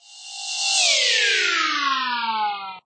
object_shrink.ogg